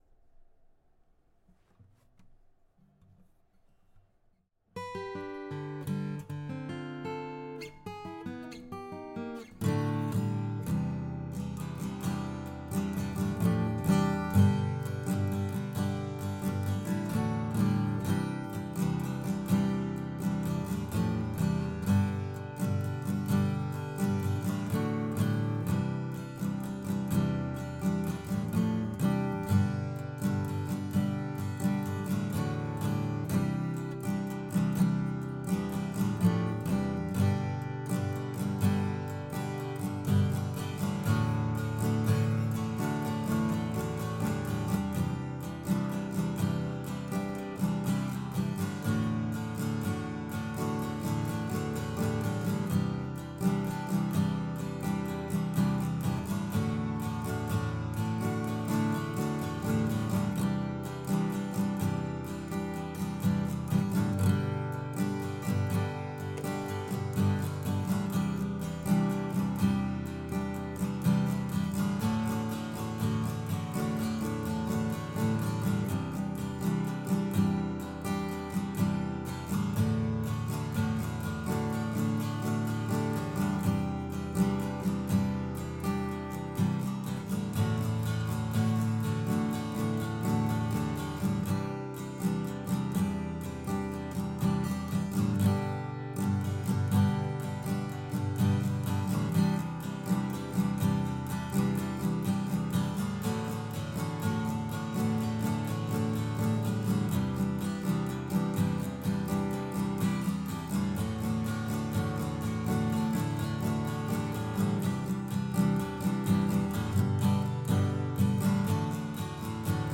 Help with mic'ing/recording acoustic guitar
X-6 : I sat on a Berber pile area rug (5x6), cross-legged, mic on my right knee.
No deadening was used. The mic was at the 12th fret angled towards the sound hole.
New medium strings and a hard pick made it slightly awkward and my strumming is uneven. On playback, this sounds muddy to my ear, probably sounds good on your end.
The high E and B medium strings don't ring out like I'm used to with lights and it was bugging me while I played. This was recorded completely dry and was not normalized.
Not as much pick noise this time, but still there - a little.